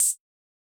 UHH_ElectroHatA_Hit-15.wav